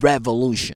REVOLUTION.wav